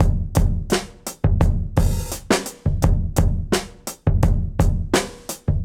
Index of /musicradar/dusty-funk-samples/Beats/85bpm
DF_BeatA_85-04.wav